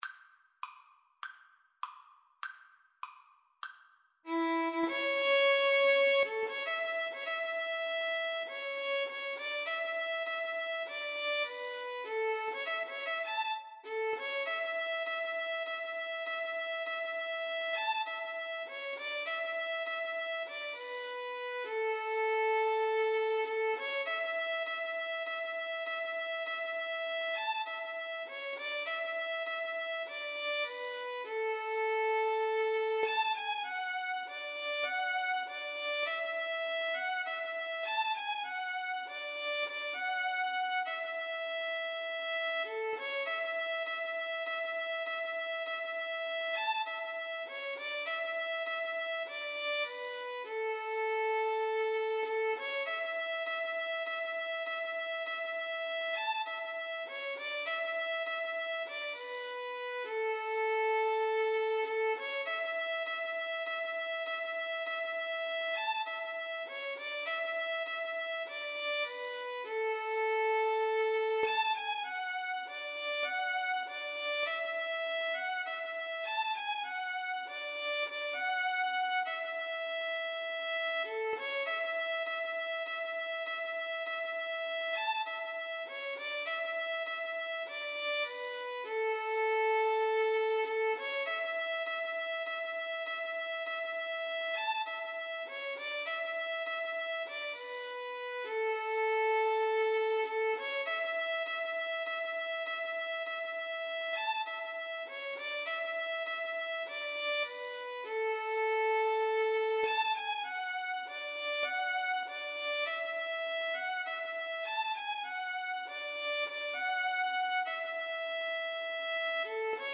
Violin 1Violin 2
2/4 (View more 2/4 Music)
A major (Sounding Pitch) (View more A major Music for Violin Duet )
March Tempo = c. 100
Violin Duet  (View more Easy Violin Duet Music)
Traditional (View more Traditional Violin Duet Music)